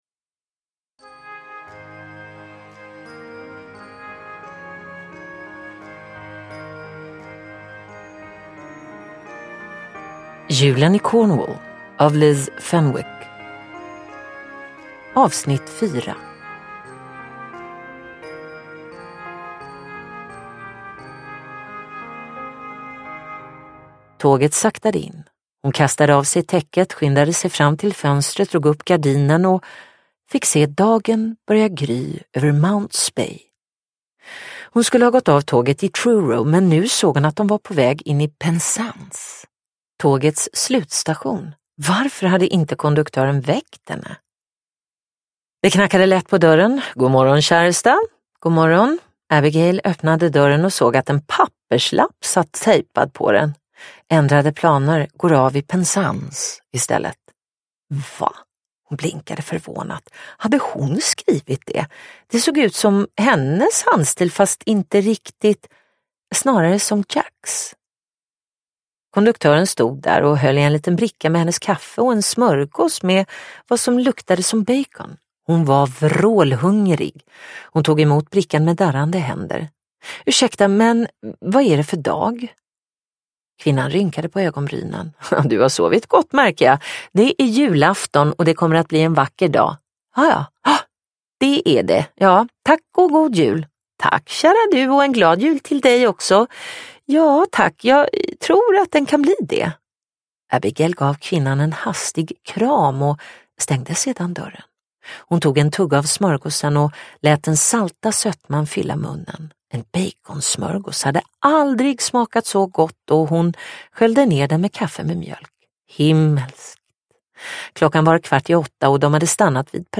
Julen i Cornwall - Del 4 : En julsaga – Ljudbok – Laddas ner